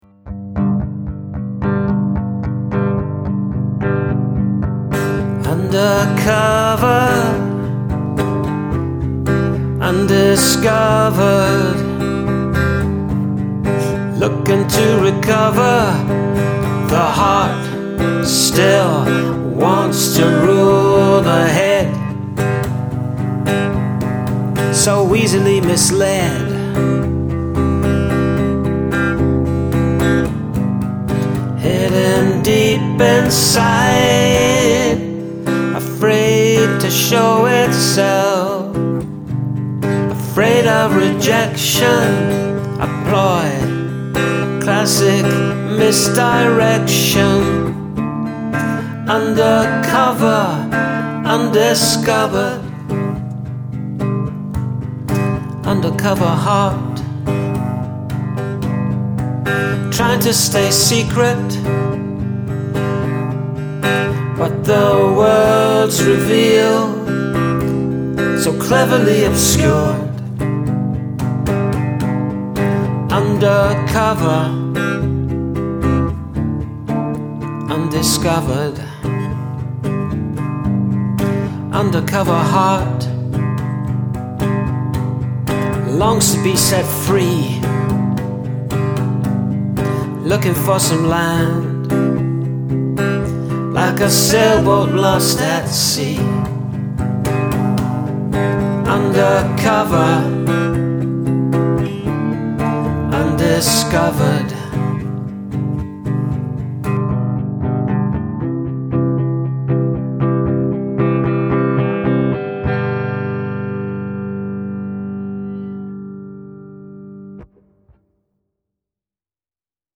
When those harmonies kick in??